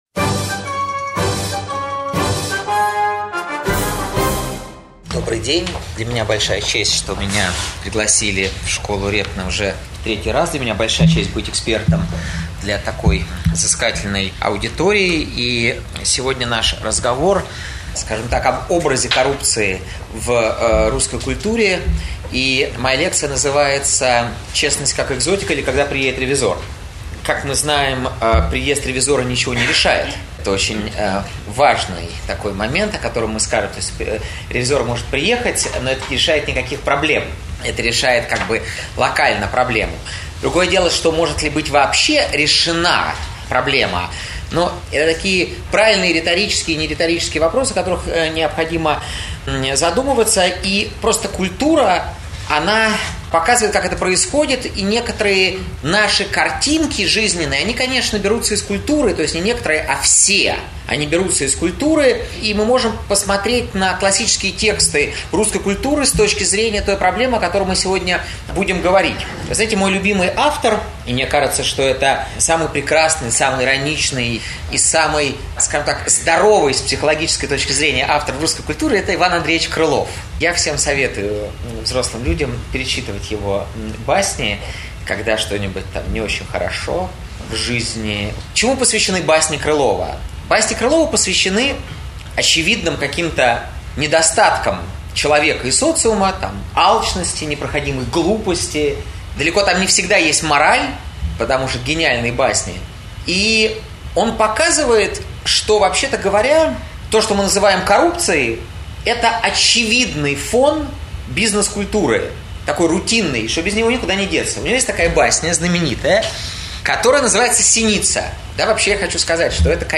Аудиокнига Когда же вы приедете, ревизор? Или кто из литературных героев берет взятки | Библиотека аудиокниг